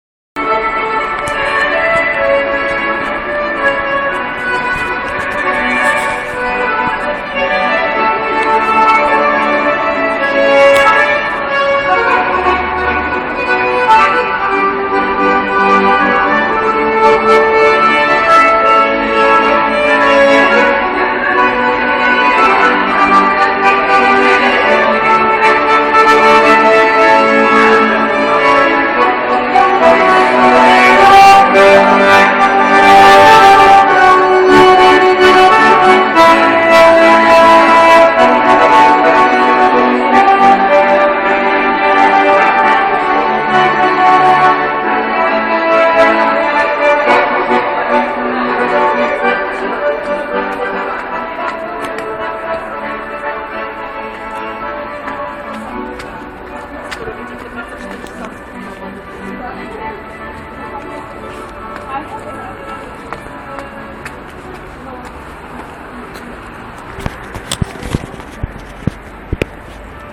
Сегодня в переходе парень на баяне исполнял мелодию.